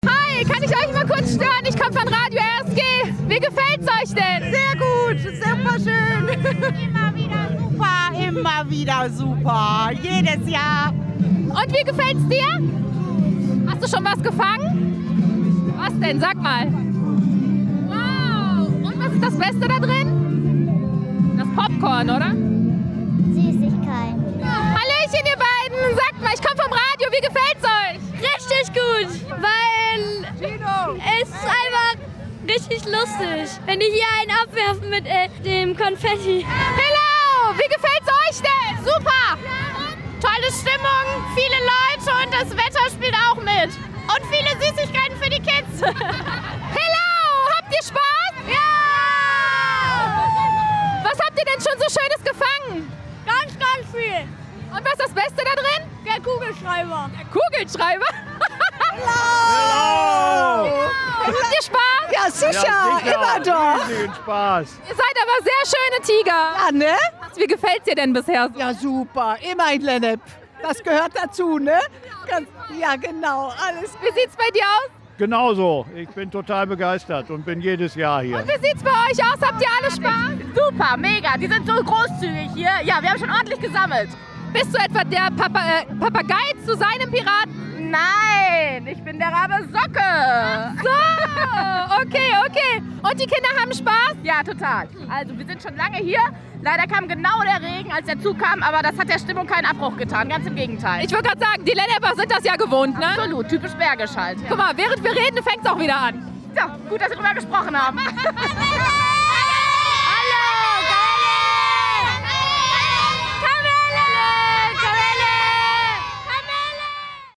Radio RSGUmfrage Rosenmontag 2024 in Lennep